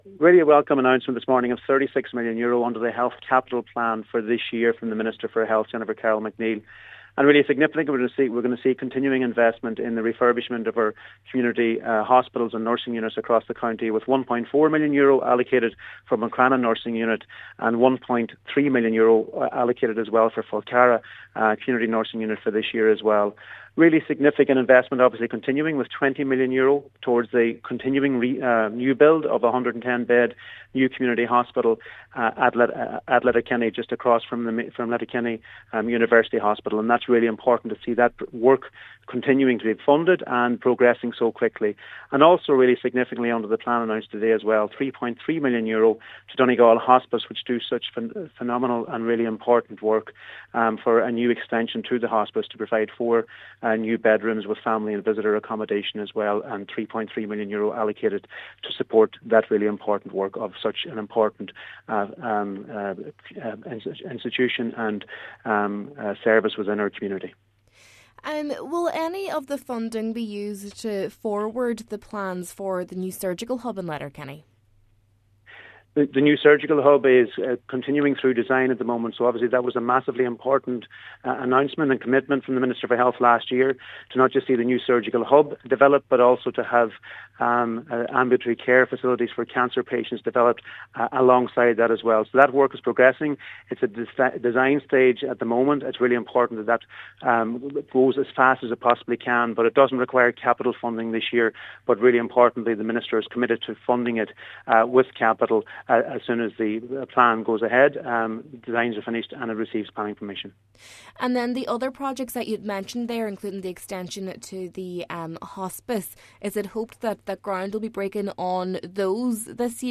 Donegal Minister Charlie McConalogue in welcoming the news, outlined some of the other projects included: